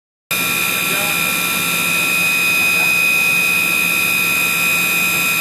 这是电机电磁声音，达到92分贝及以上。